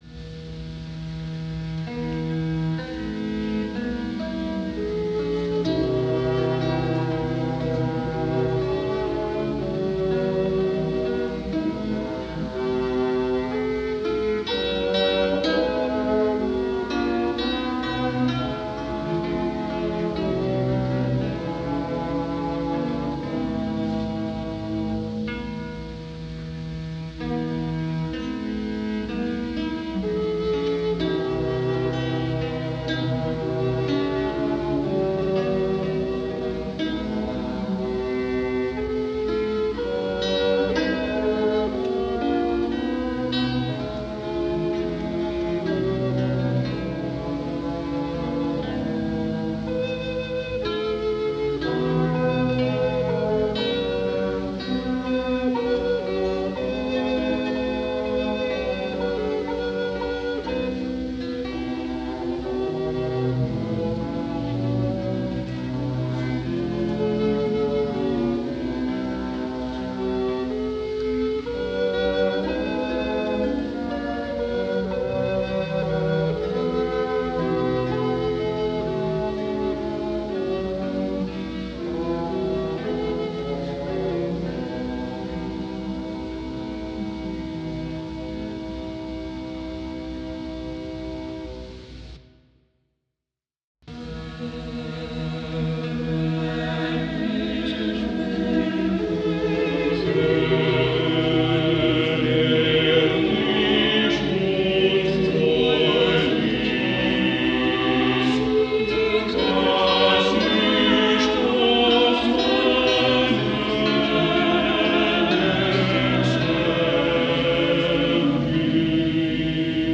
This is the more polyphonic/imitative of the two settings of a striking melody (folk-song?) that the composer made.  A Tenorlied, it is first played on the sackbutt
baritone